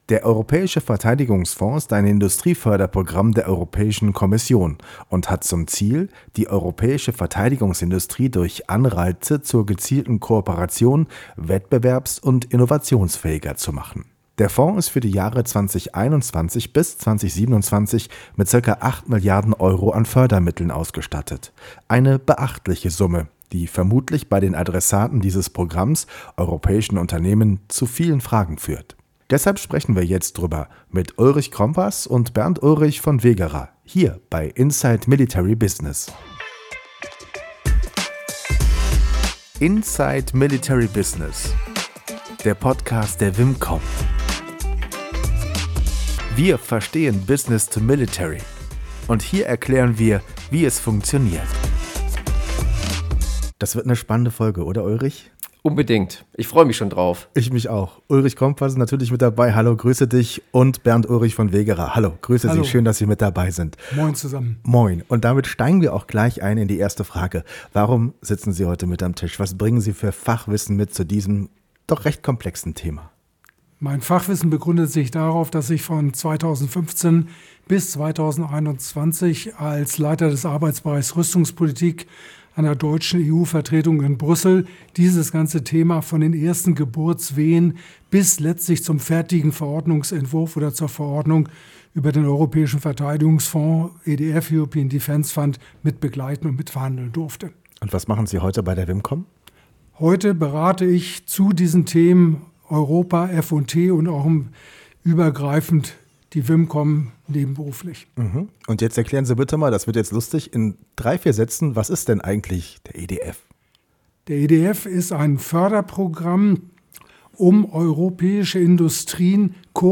Hintergrund: Das ist der Europäische Verteidigungsfonds. General a.D. Zorn zu Gast im WIMCOM-Podcast.